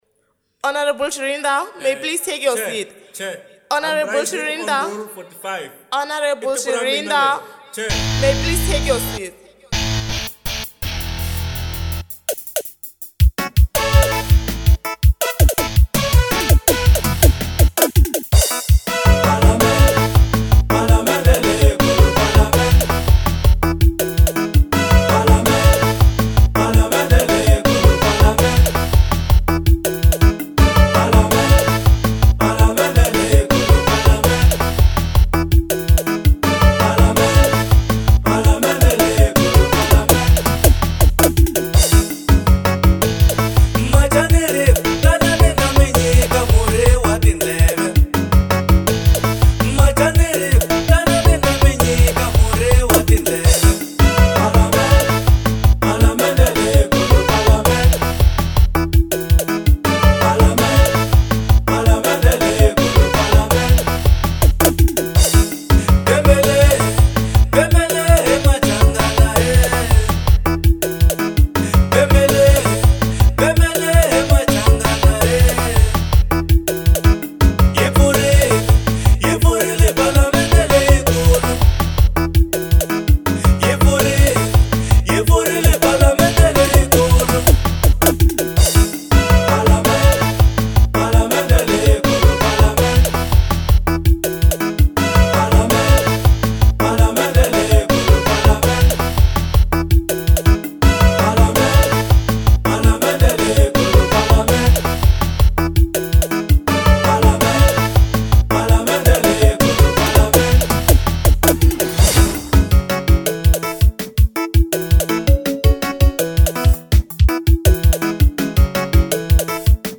Genre : Xitsonga